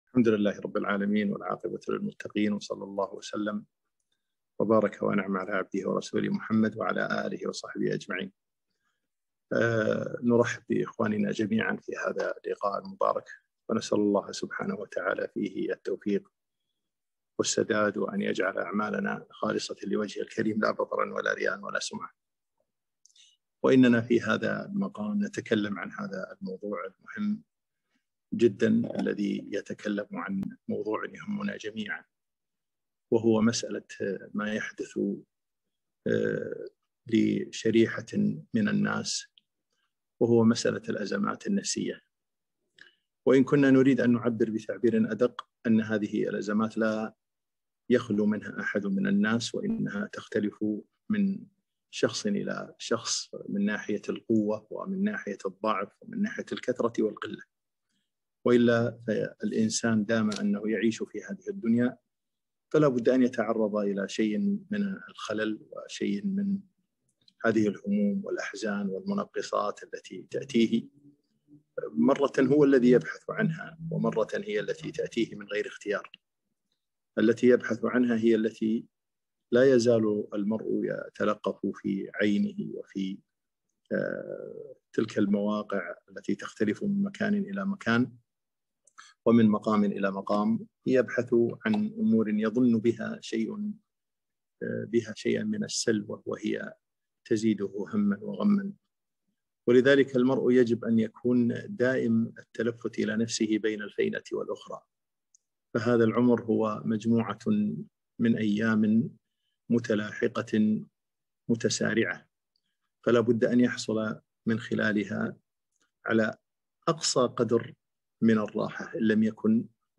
محاضرة - الأزمات النفسية وكيف نتعامل معها في ضوء الكتاب والسنة